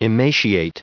1279_emaciation.ogg